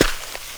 Index of /90_sSampleCDs/AKAI S6000 CD-ROM - Volume 6/Human/FOOTSTEPS_2
SNEAK DIRT 2.WAV